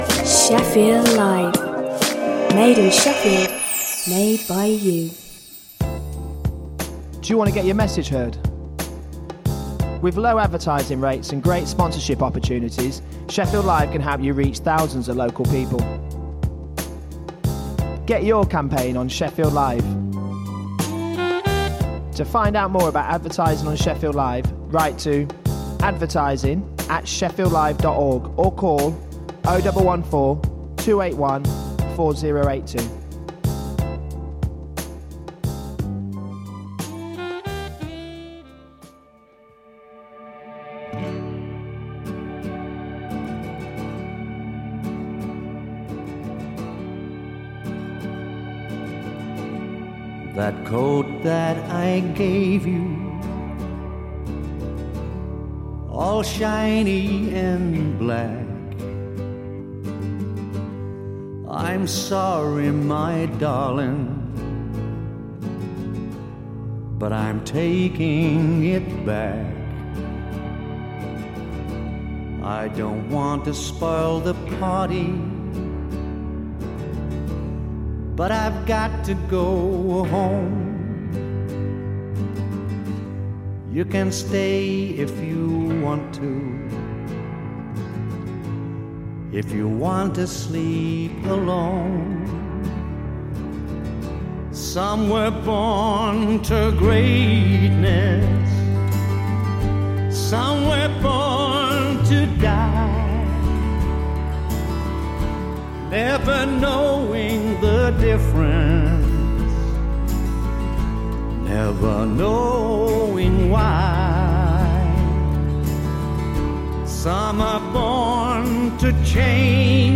Information about radical environmental projects, innovative regeneration activities, views on the city’s development and off-the-wall cultural projects with a wide range of music from across the world.